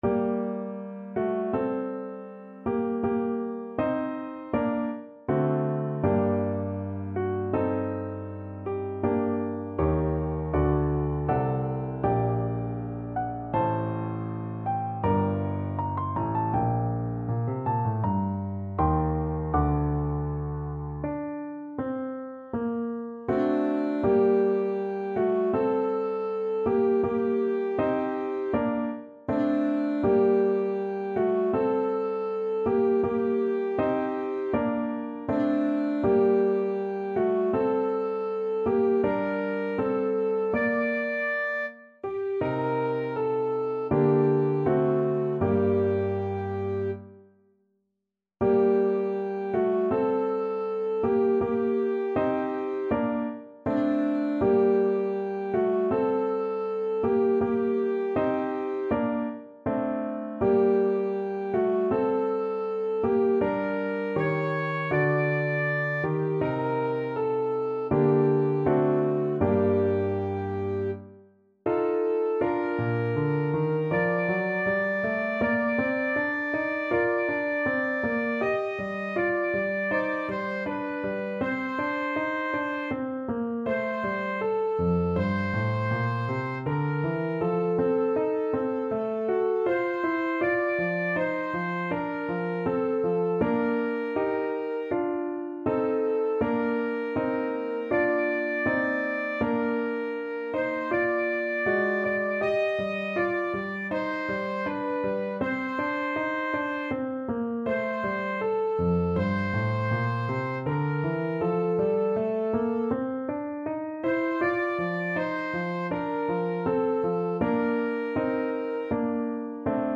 Isaac Nathan - Oh! weep for those Free Sheet music for Alto Saxophone
Classical
Key: G minor (Sounding Pitch)
Tempo Marking: Andante =c.80
Time Signature: 4/4